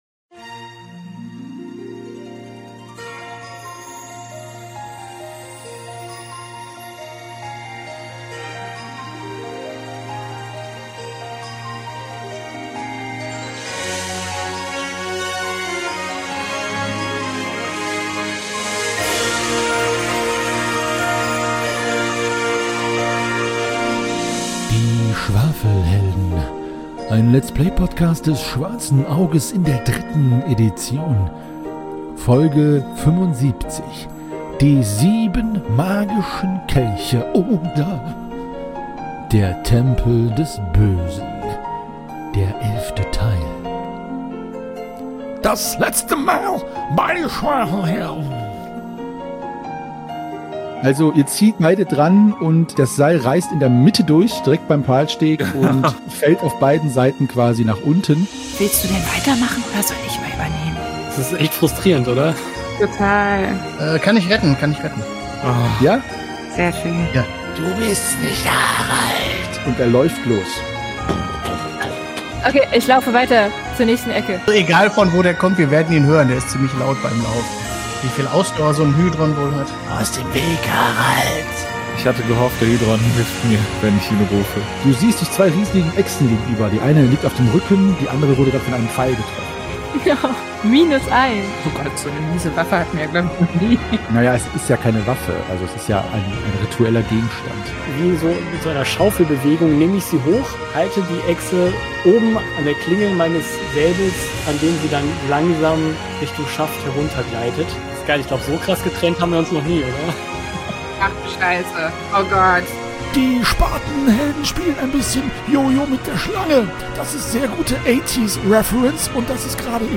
Ein Let’s Play Podcast für Das Schwarze Auge, das deutsche Fantasyrollenspiel. Wir spielen DSA in der 3. Edition und haben uns vorgenommen, alle Abenteuer in Reihenfolge ihrer Veröffentlichung zu spielen.